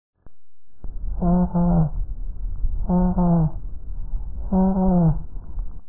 • La transmisión de la voz es una maniobra de la auscultación que consiste en escuchar con el estetoscopio sobre el tórax del paciente mientras este habla.
• Hallazgo Normal: Broncofonía
No deberías poder distinguir claramente las sílabas de la palabra "treinta y tres". A este hallazgo normal se le llama broncofonía fisiológica.
5Broncofonia.mp3